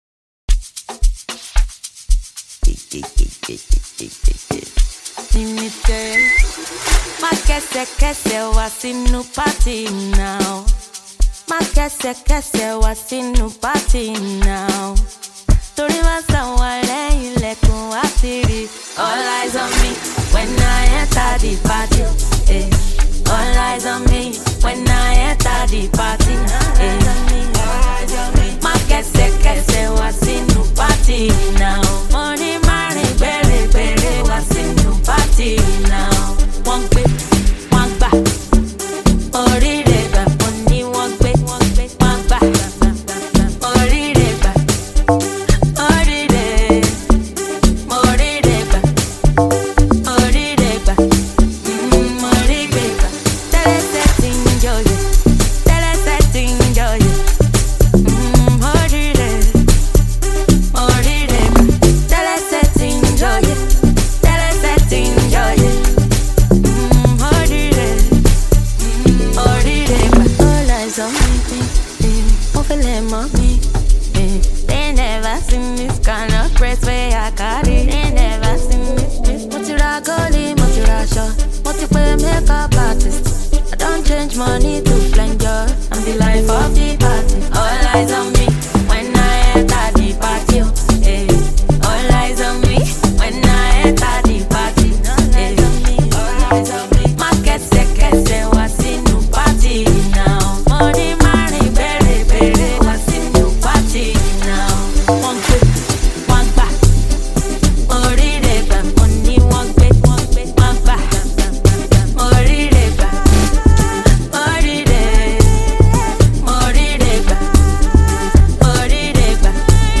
Nigeria’s queen of Afro-House
a bumpy Amapiano record with captivating melodies